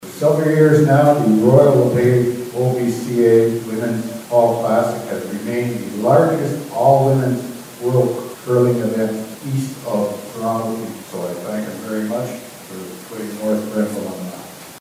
The event was held last night during the council meeting.